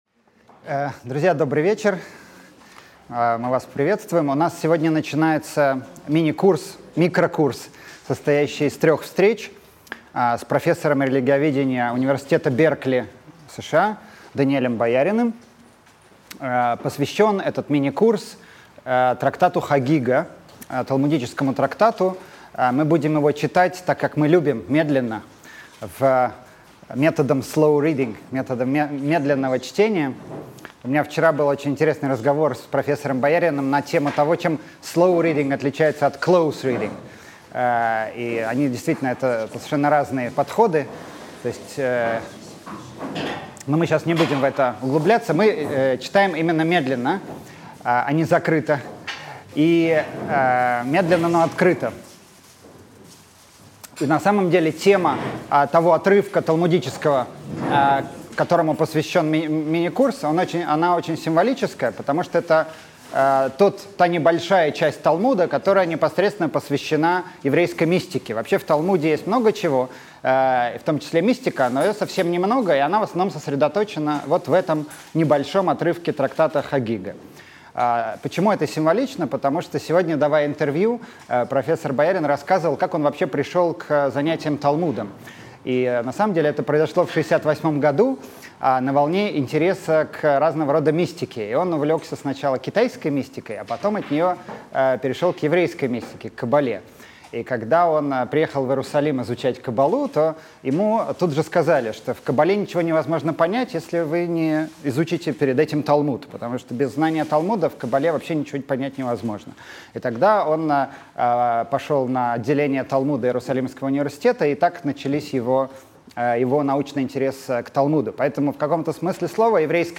Аудиокнига Мистические главы Талмуда. Часть 1 | Библиотека аудиокниг